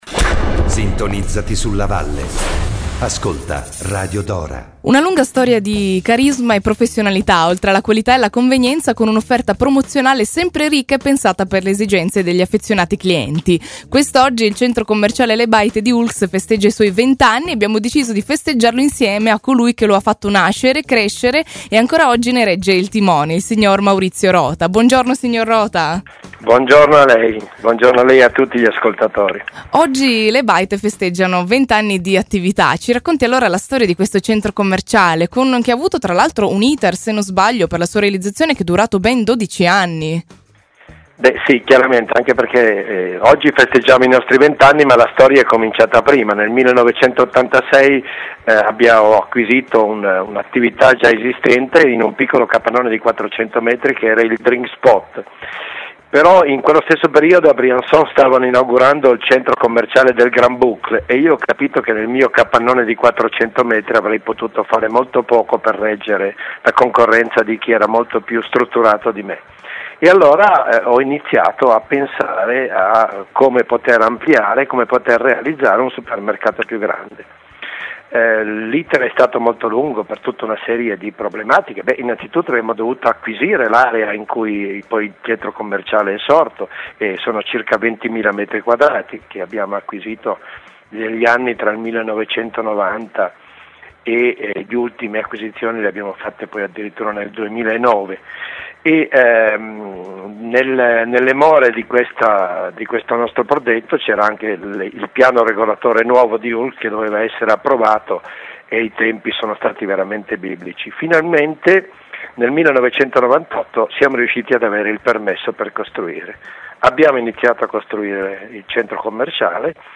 Home Intervista Buon compleanno al Centro Commericale Le Baite di Oulx! 13 Luglio 2019 Buon compleanno a Le Baite di Oulx!